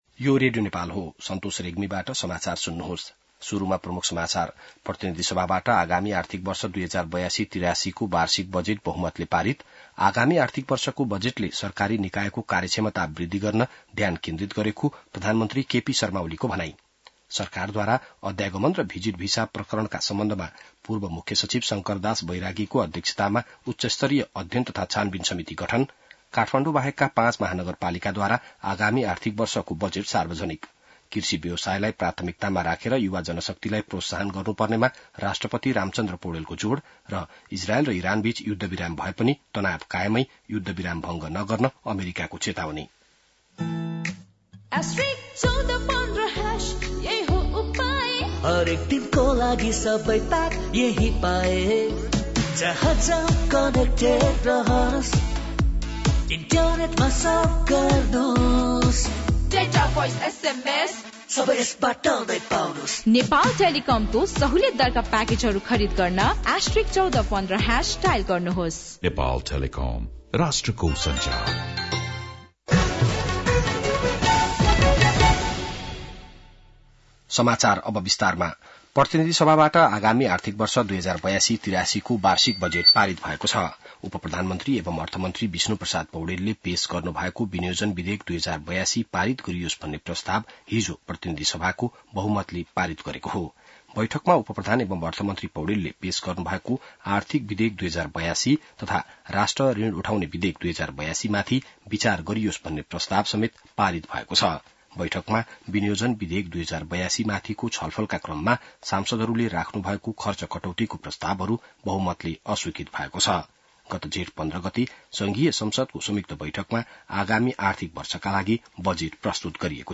बिहान ७ बजेको नेपाली समाचार : ११ असार , २०८२